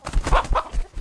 combat / creatures / CHİCKEN / he / attack3.ogg